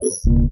ui_close.mp3